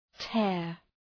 Προφορά
{teər}